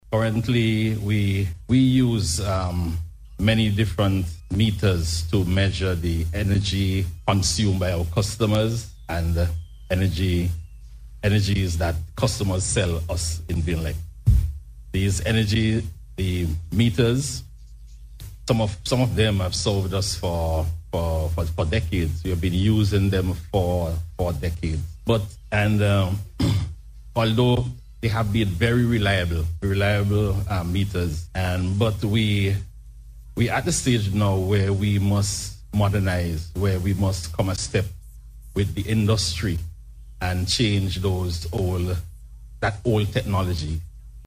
This, with the launch of VINLEC’s Smart Meter Project at the Methodist Church Hall on Monday.
Delivering remarks at the launching ceremony